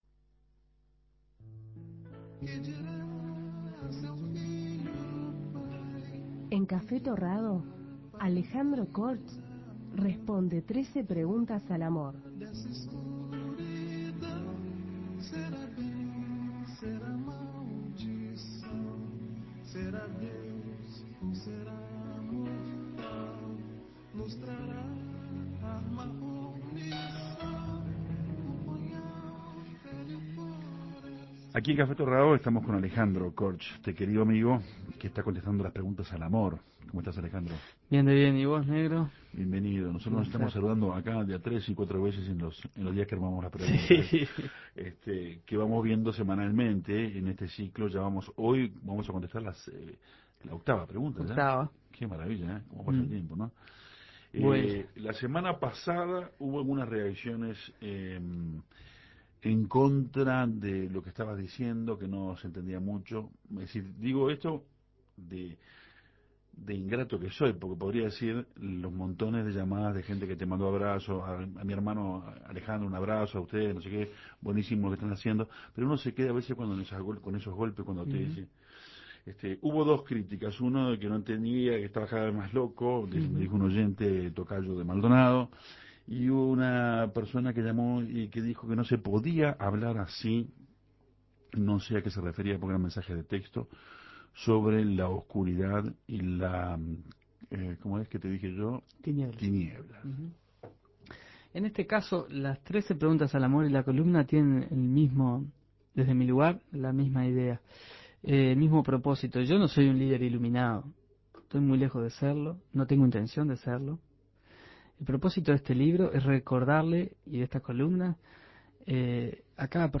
"La enfermedad" fue la elegida para esta ocasión. Escuche la entrevista.